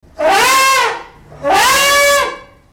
Elephant Triumph - Sfx Bouton sonore